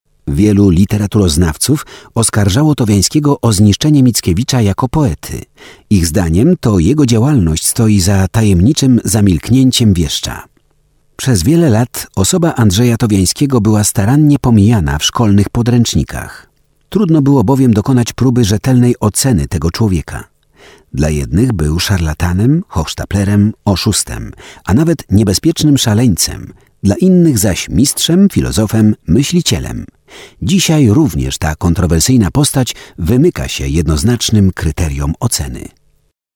Sprecher polnisch für TV / Rundfunk / Industrie.
Sprechprobe: Sonstiges (Muttersprache):
Professionell voice over artist from Poland.